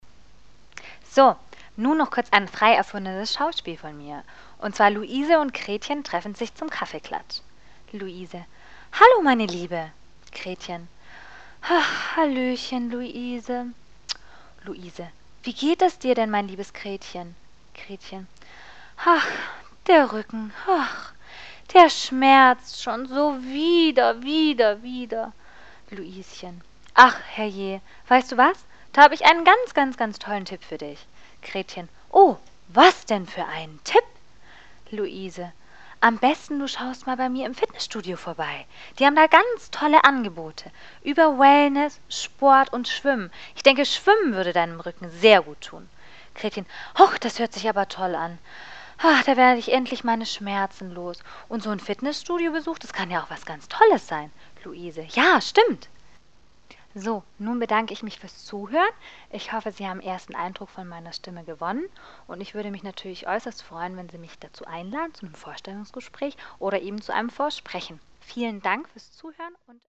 Flexibel
Kein Dialekt
Sprechprobe: eLearning (Muttersprache):